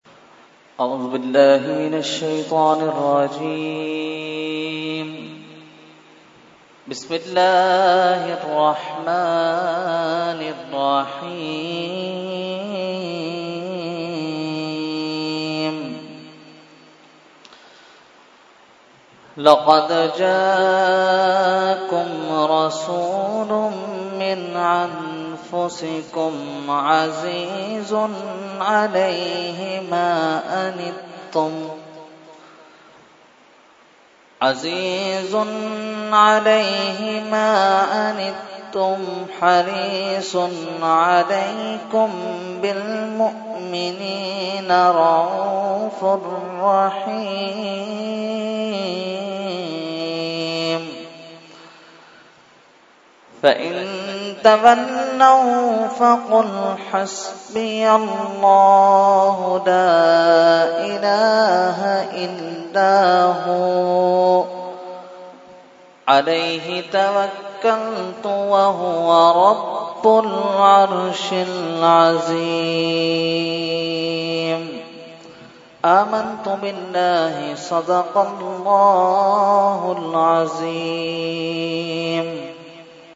Qirat – Hizbul Bahr 2018 – Dargah Alia Ashrafia Karachi Pakistan